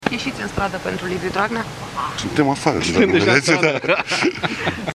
Întrebat dacă va paricipa la un asemenea miting, premierul României, Mihai Tudose a răspuns râzând: „Suntem deja afară”!